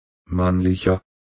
Mannlicher (pronounced
mannlicher_german.mp3